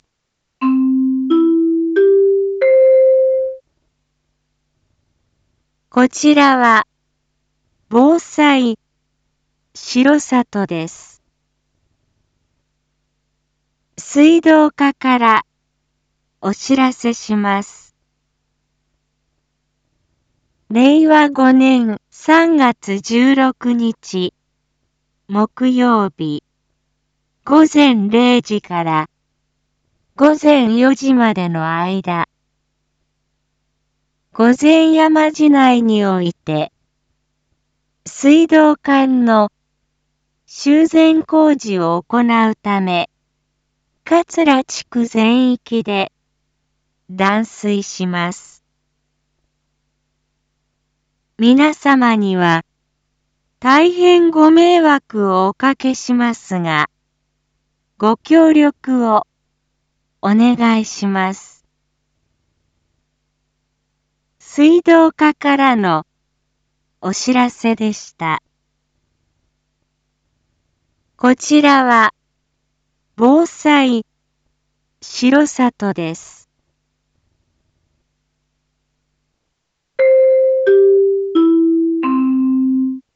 Back Home 一般放送情報 音声放送 再生 一般放送情報 登録日時：2023-03-15 07:06:24 タイトル：水道断水のお知らせ（桂地区限定） インフォメーション：こちらは、防災しろさとです。